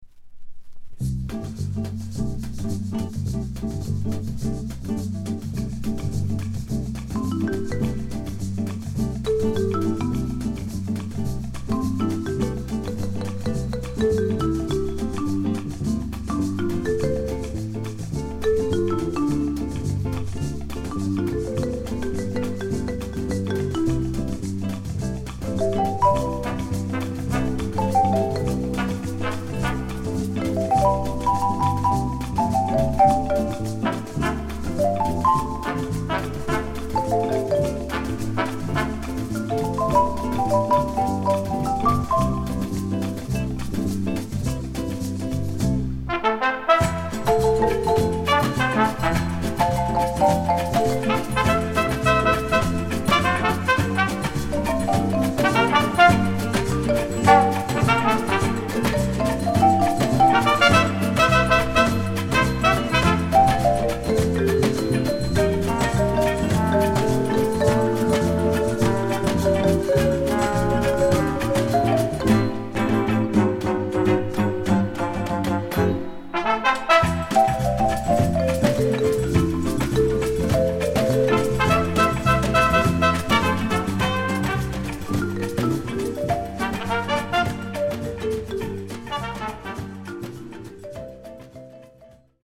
Fusion, brass and percussion